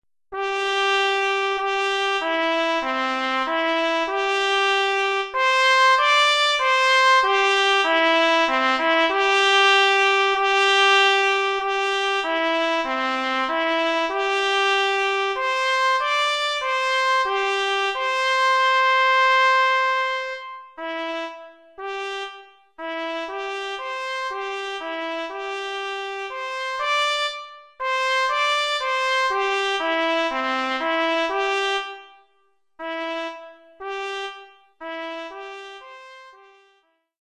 Trompette de cavalerie Mib
Oeuvre pour trompette mib seule.